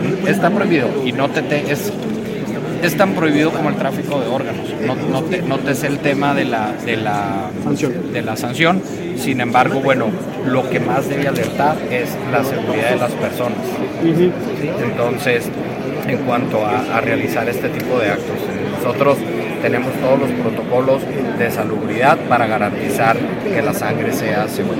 AUDIO: GILBERTO BAEZA MENDOZA, SECRETARIO DE SALUD ESTATAL (SS) 2